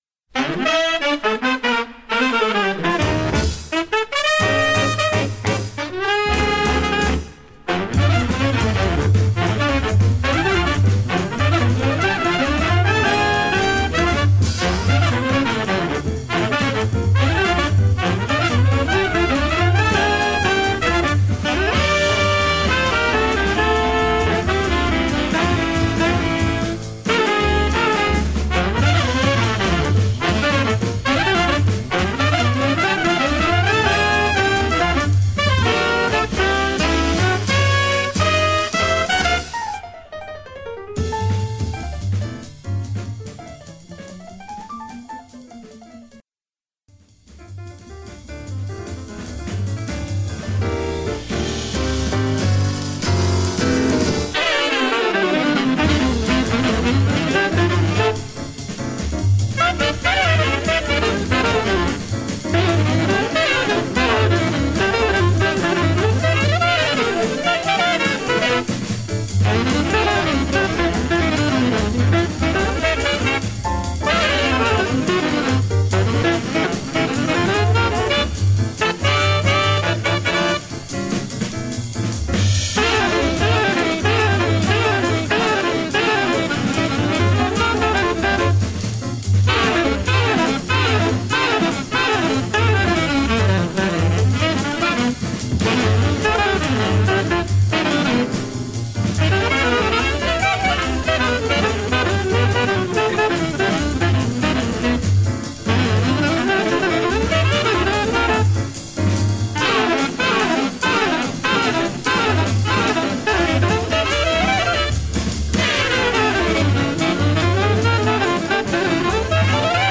Voicing: Saxophone Quintet